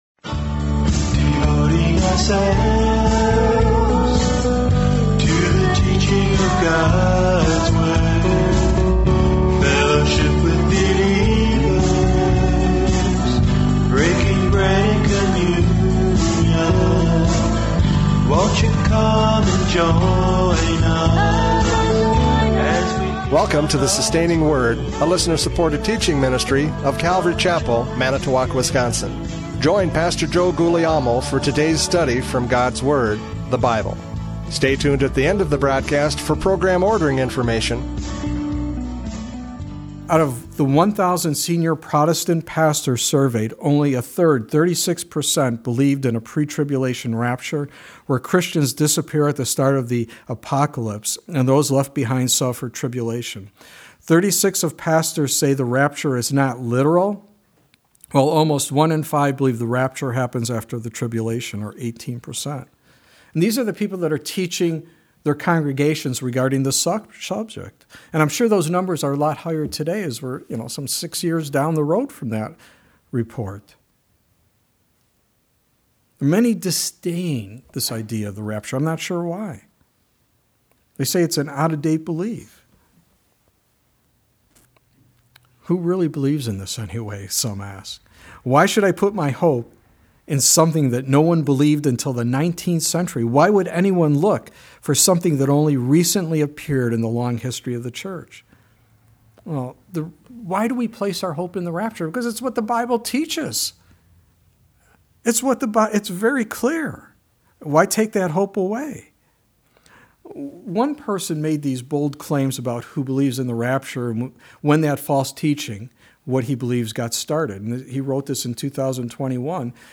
John 14:1-4 Service Type: Radio Programs « John 14:1-4 The Comfort of Christ!